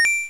win.wav